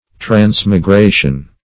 Transmigration \Trans`mi*gra"tion\, n. [F. transmigration, L.